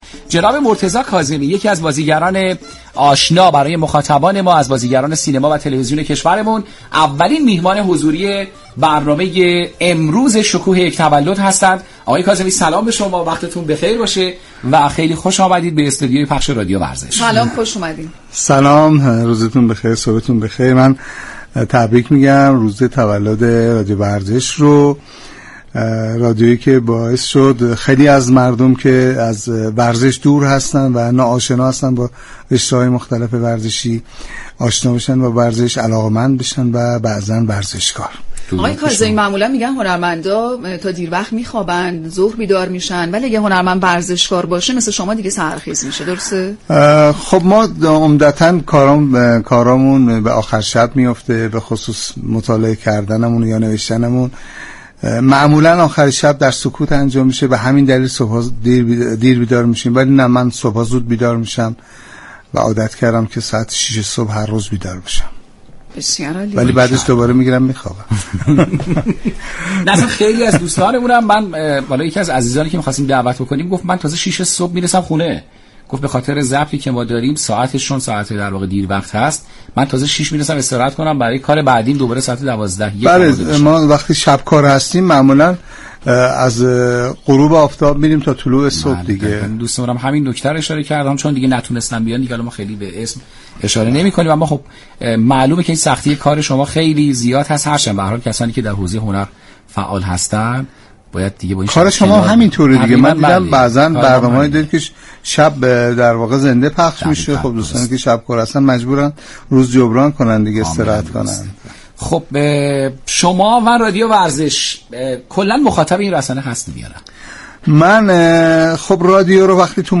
مرتضی كاظمی، بازیگر محبوب سینما و تلویزیون مهمان ویژه برنامه شكوه یك تولد به مناسبت سالروز تاسیس شبكه رادیویی ورزش شد.
مرتضی كاظمی در گفتگویی صمیمی از فعالیت ورزشی هنرمندان و نیز حمایت آنان از كودكان كار گفت.